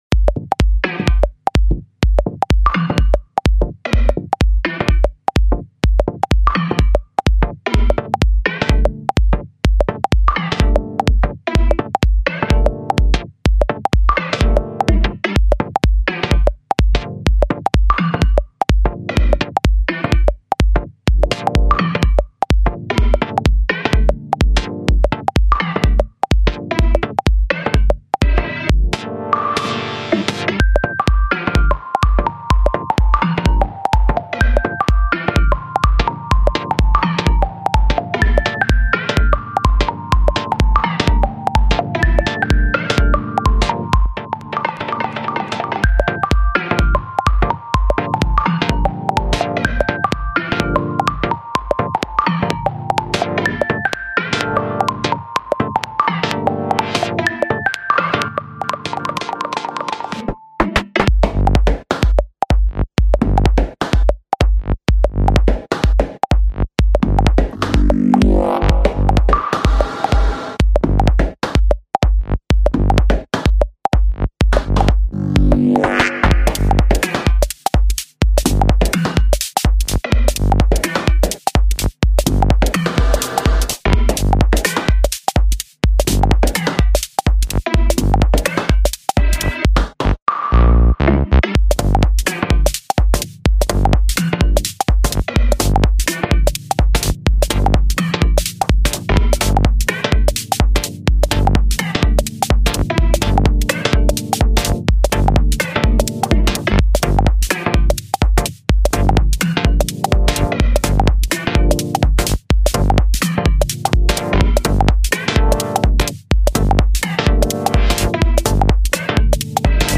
techno minimalista
Minimal, Techno, Tech-house y electrónica hardcore